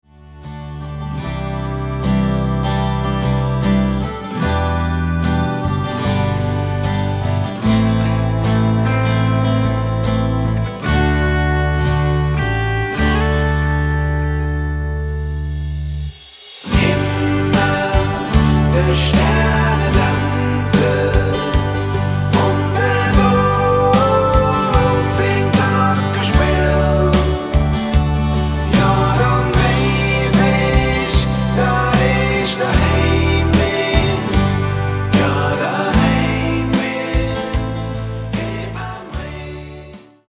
--> MP3 Demo abspielen...
Mit Backing Vocals